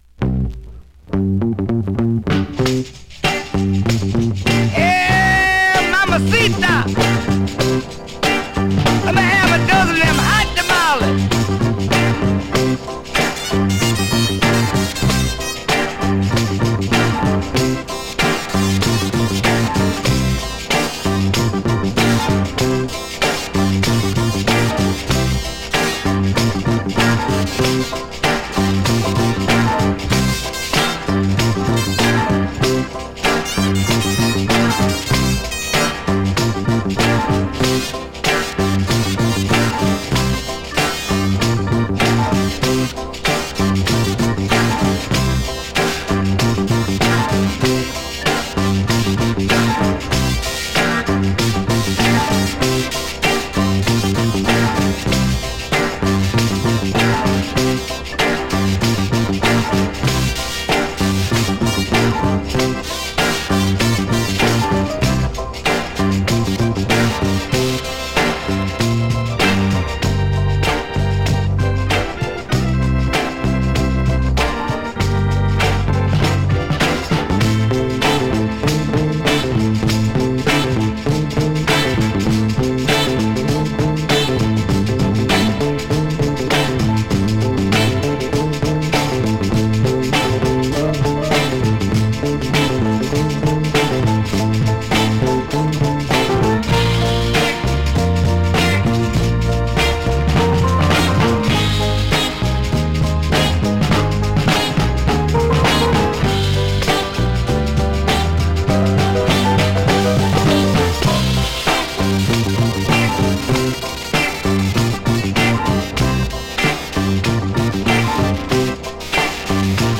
you can hear him playing piano
fuzz guitar